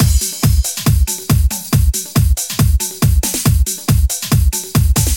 • 139 Bpm Nineties Breakbeat C# Key.wav
Free drum loop - kick tuned to the C# note. Loudest frequency: 2453Hz
139-bpm-nineties-breakbeat-c-sharp-key-tU0.wav